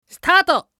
男性
☆★☆★システム音声☆★☆★